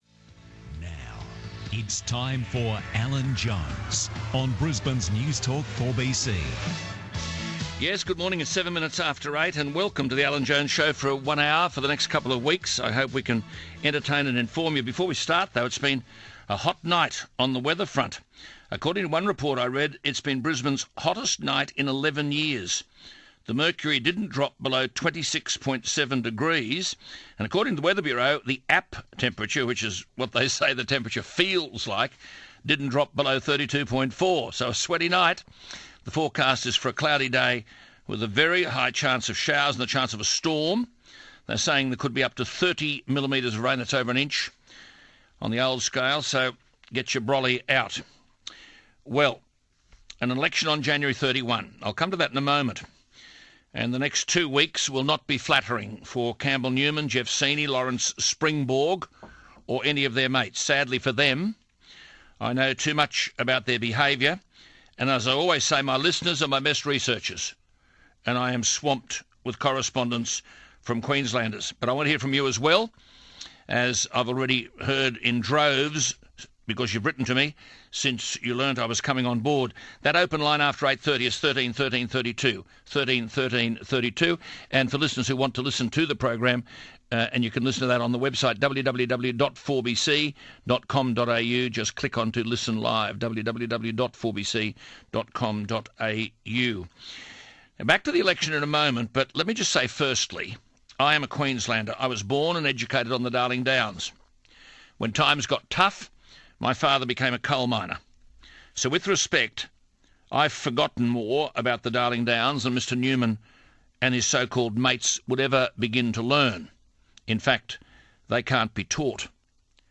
4BC's Queensland election hosted by, Alan Jones.